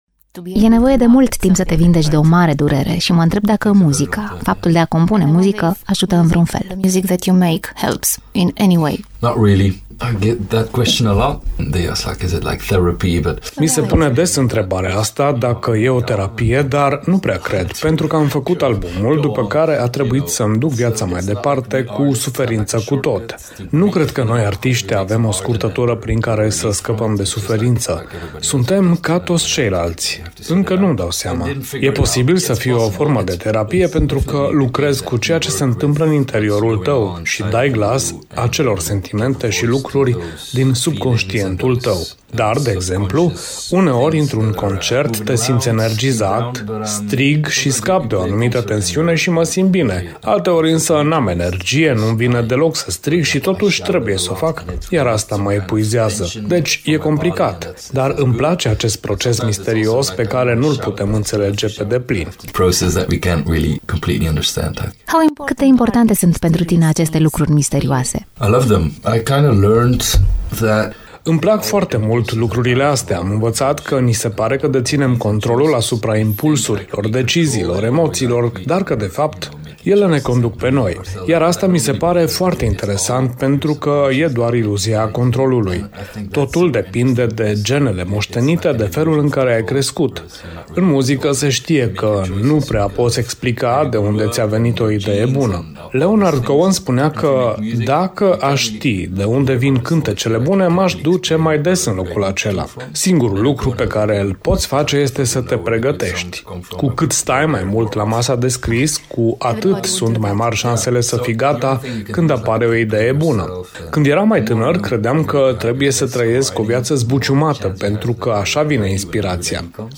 Interviul în limba engleză poate fi ascultat aici: